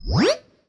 sci-fi_shield_device_small_01.wav